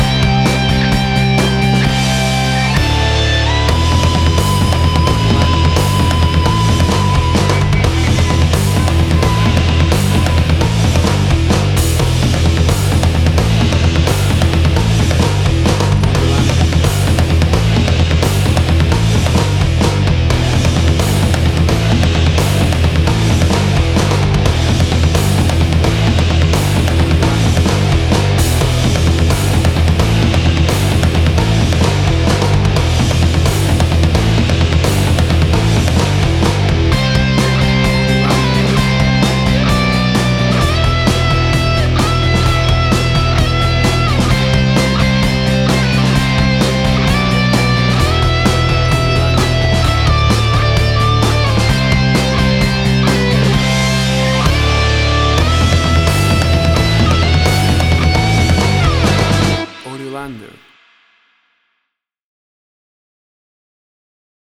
Tempo (BPM): 130